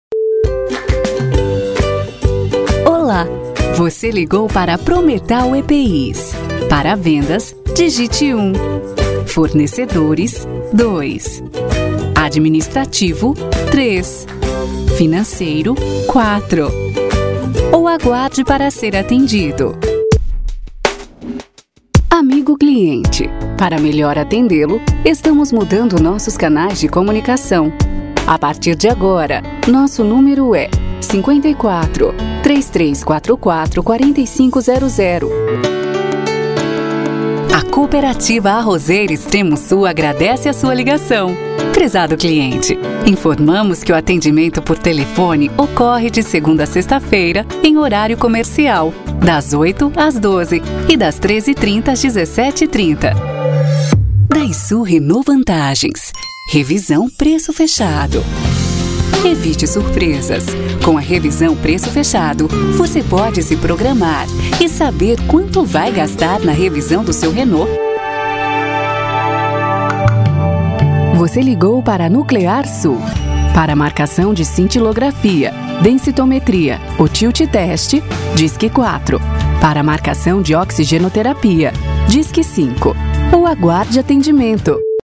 • espera telefonica
Mix de Esperas